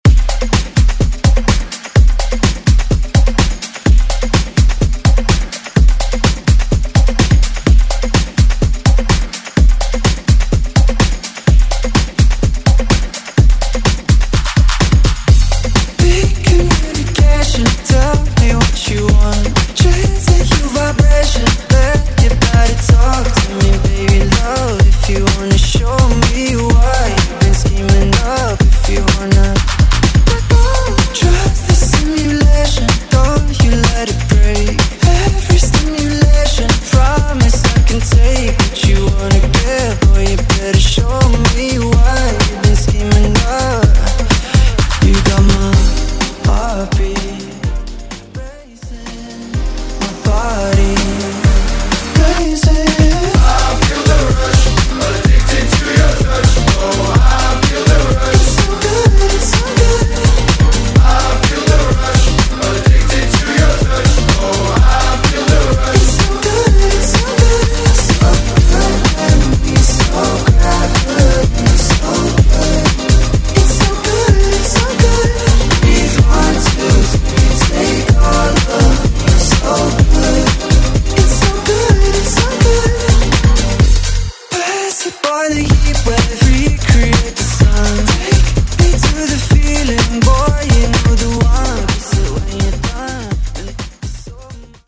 Genres: RE-DRUM , TOP40 Version: Clean BPM: 103 Time